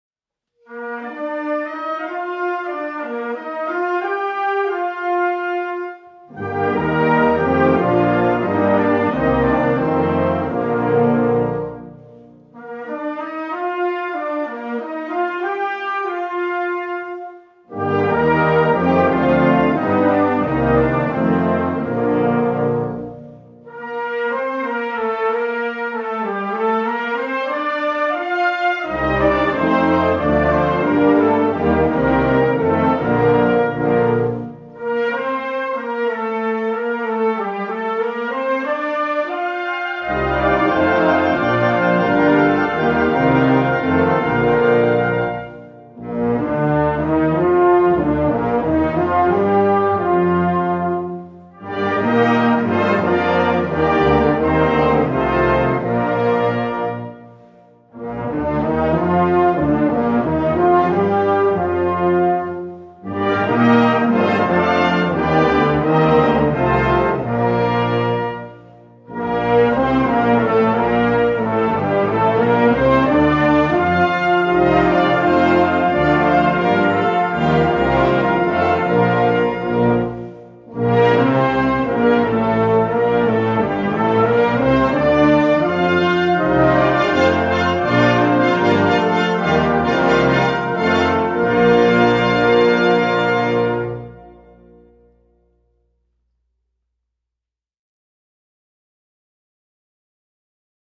Gattung: Messe für Blasorchester
Besetzung: Blasorchester